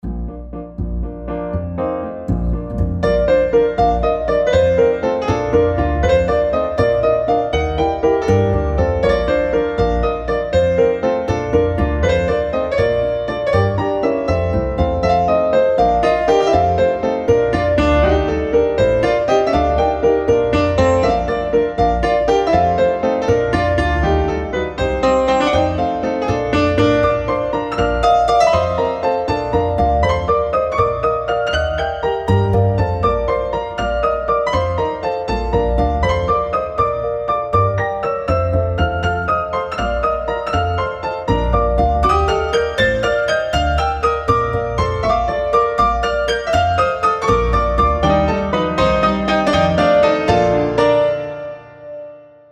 Jacobis An Chloen / „Chloe! kennst du noch…“, die Melodie wurde von Johann Friedrich Reichardt (1752-1814) übernommen, der Satz im adretten Barpiano-Stil neu komponiert: